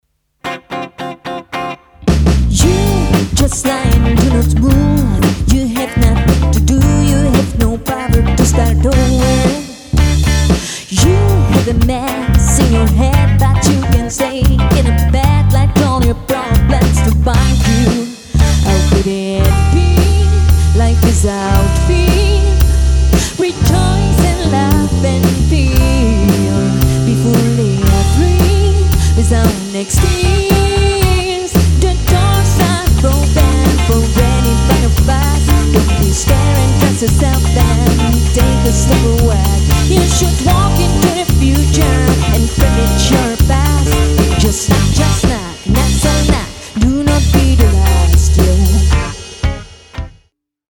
V následující demo nahrávce jsou tedy v počítači udělané bicí, a taky tam ještě chybí piano, ale schválně poslechněte. Střídám tam Music Man StingRay 5 a Fender Precision Bass Custom Shop, obojí s roundwound strunami v dobrém stavu.
Ukázka s kapelou